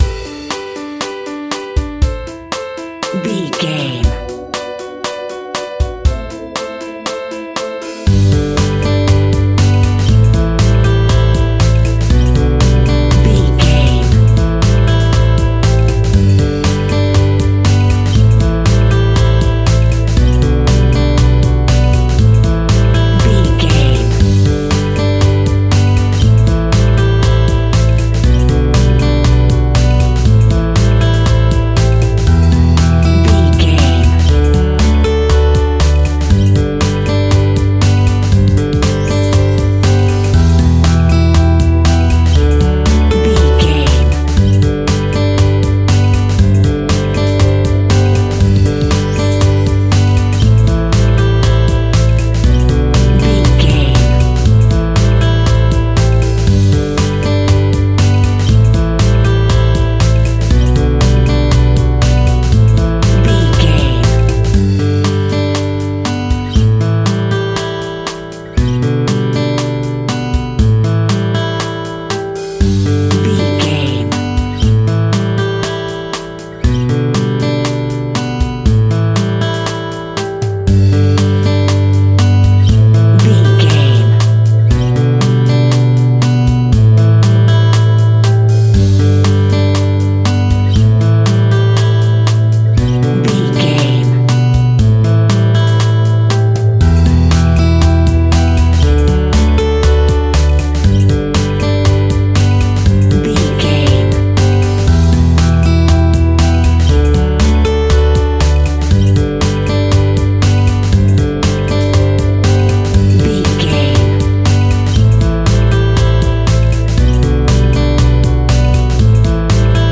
Ionian/Major
pop rock
indie pop
energetic
uplifting
synths
drums
bass
guitar
piano